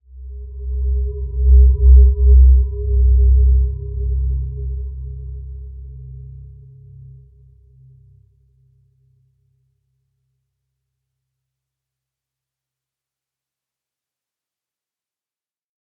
Dreamy-Fifths-C2-mf.wav